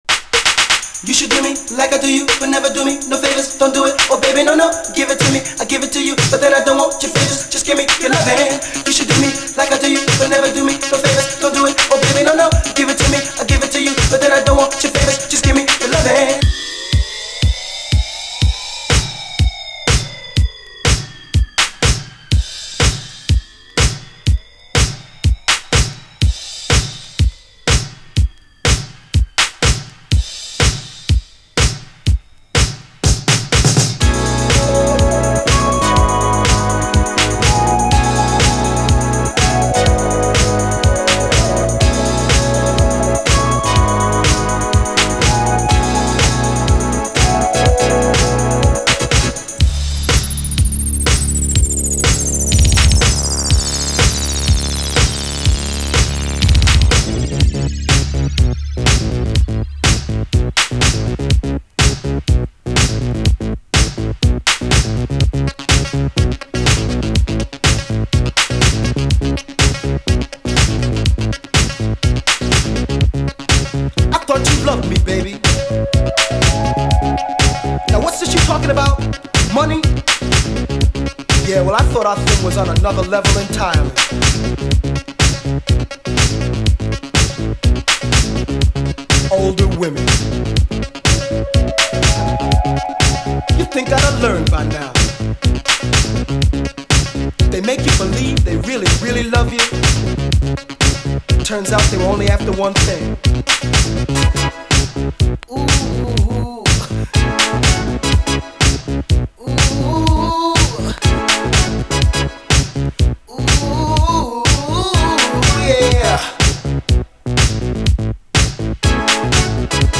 DISCO
最高NYシンセ・ブギー！イントロから超カッコよく、キャッチーでギラギラしたシンセ・サウンドが輝く！